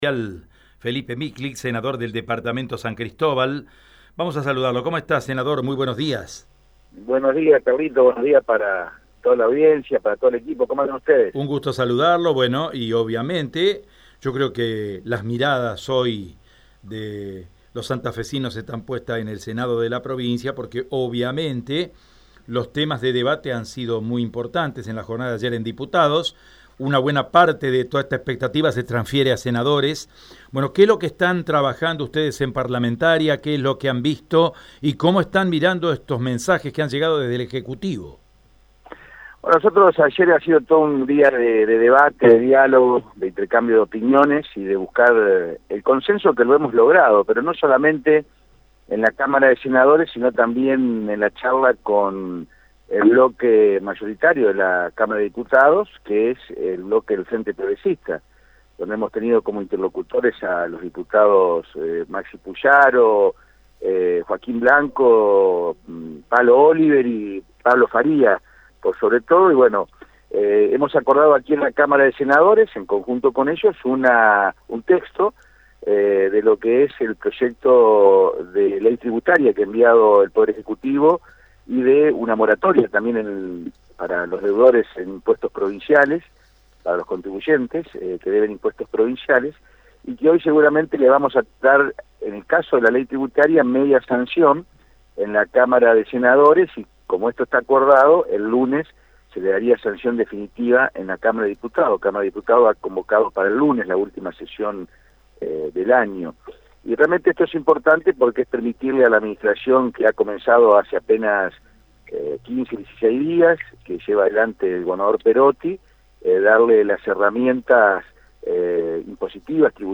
El senador provincial Felipe Michlig, en diálogo con Radio EME dijo que en el día de hoy van a tratar la Ley Tributaria y el Consenso Fiscal 2019 . Entre otras otras cosas también habló de la posibilidad de llegar a un acuerdo para el congelamiento de dietas.